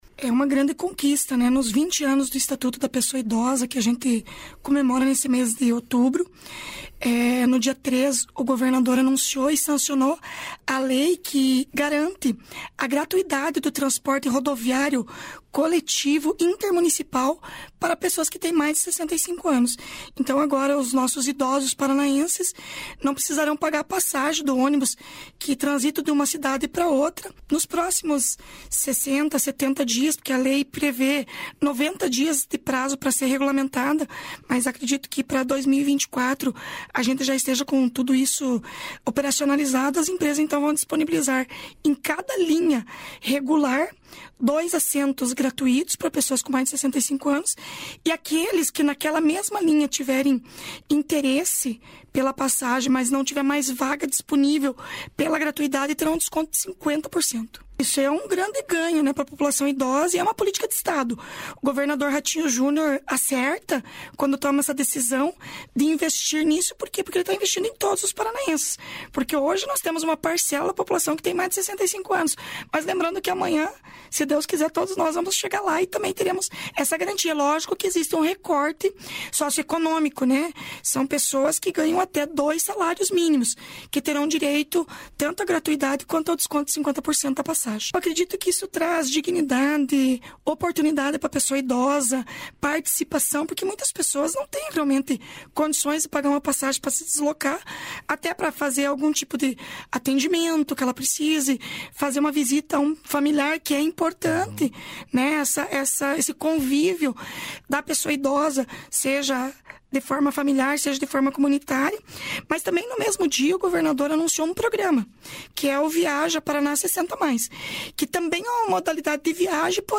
Sonora da secretária da Mulher, Igualdade Racial e Pessoa Idosa, Leandre Dal Ponte, sobre as ações para os idosos